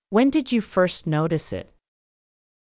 ｳｪﾝ ﾃﾞｨｯ ｼﾞｭｰ ﾌｧｰｽﾄ ﾉｳﾃｨｽ ｲｯﾄ